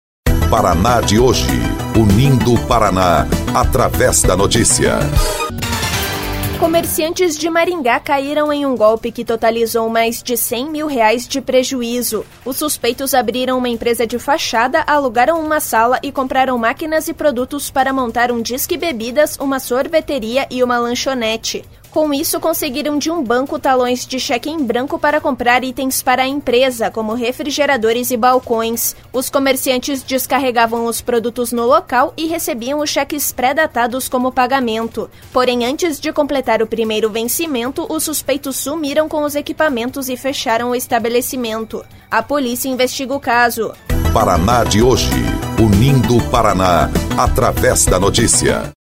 BOLETIM – Golpistas abrem empresa de fachada e dão prejuízo de mais de R$100 mil